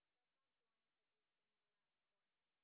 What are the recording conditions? sp30_white_snr0.wav